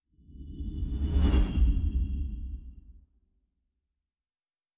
Distant Ship Pass By 5_2.wav